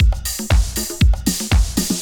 Razz Beat 2_119.wav